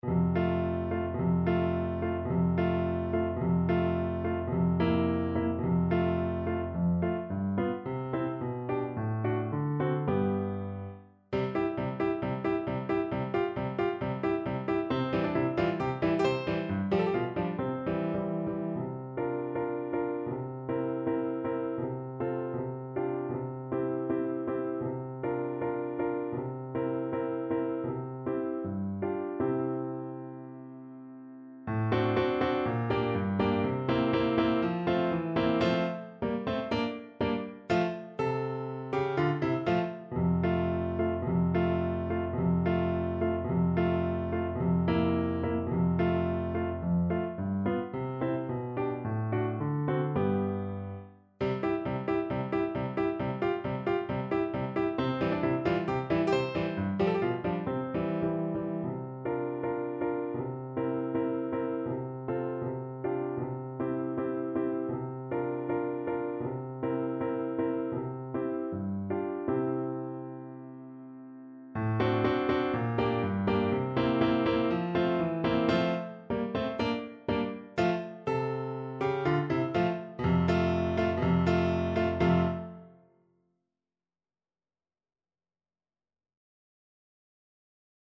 Moniuszko: Krakowiaczek “Wesół i szczęśliwy” (na klarnet i fortepian)
Symulacja akompaniamentu